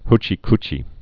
(hchē-kchē)